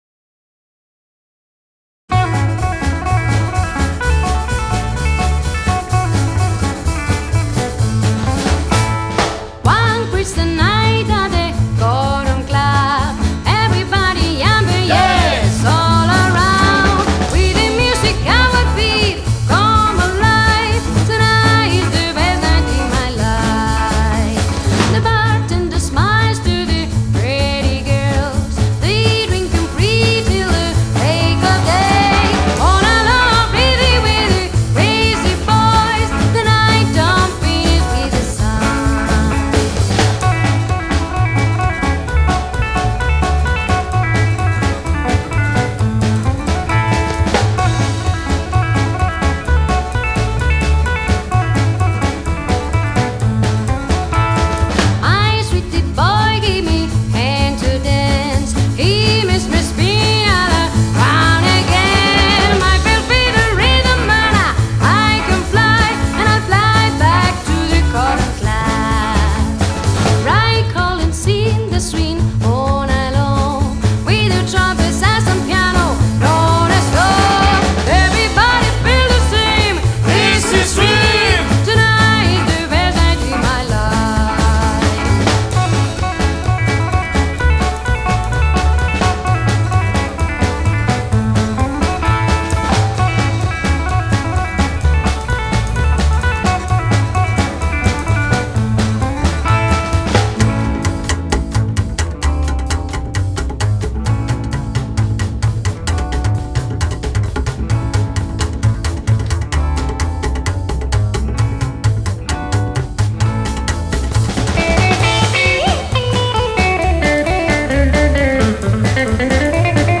› Rock › Rock & Roll › Rockabilly Album(s